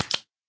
flop4.ogg